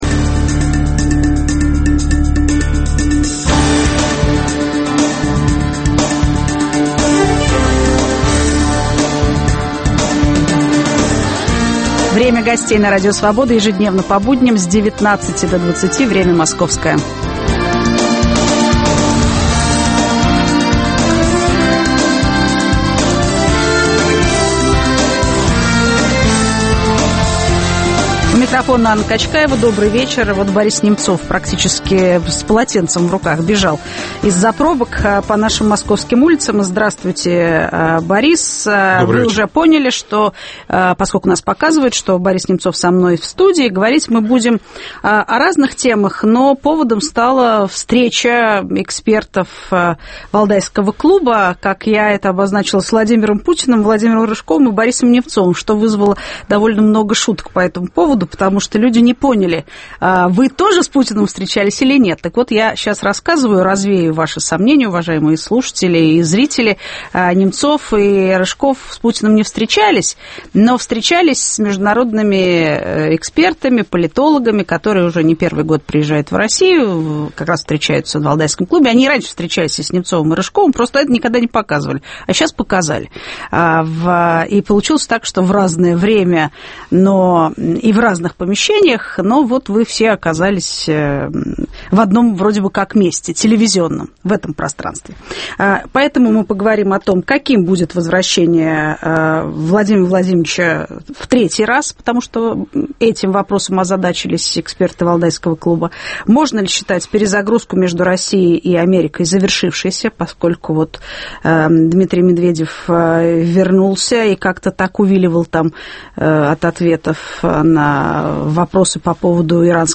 В студии - политик Борис Немцов.